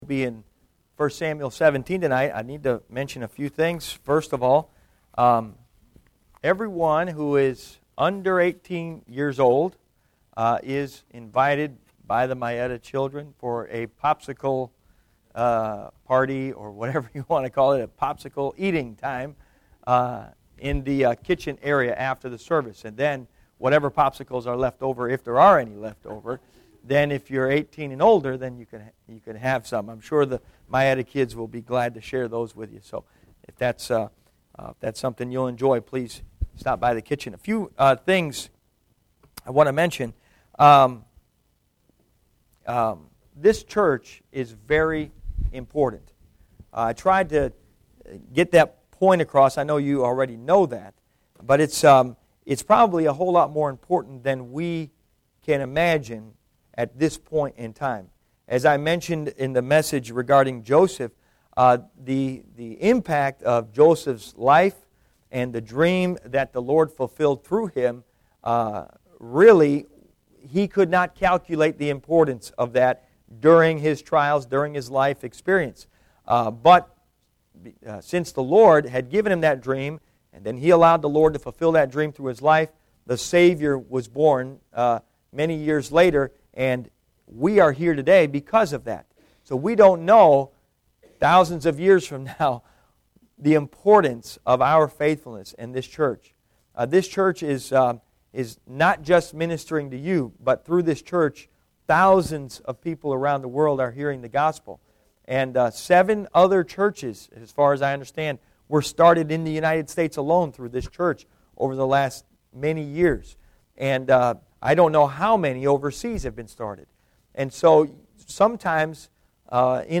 Passage: 1 Samuel 17 Service Type: Sunday PM Bible Text